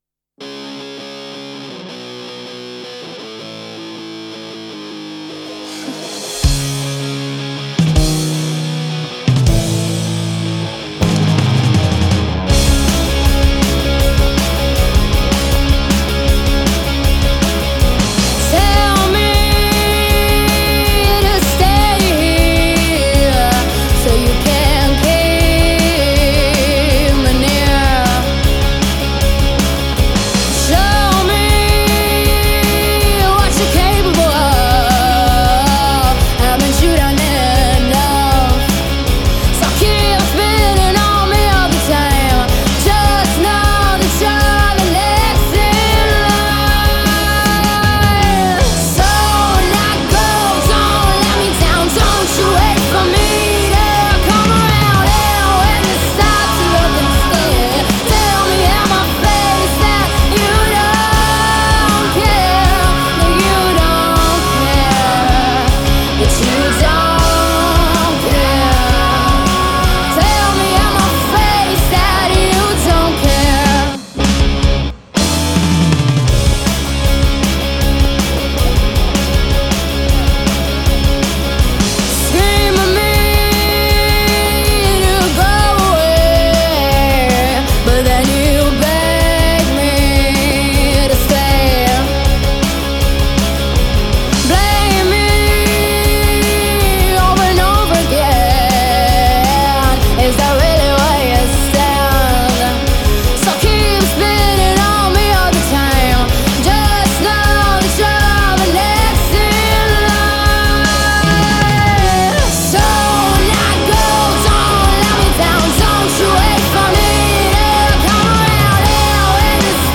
rock band
cantante
bassista
chitarrista
batterista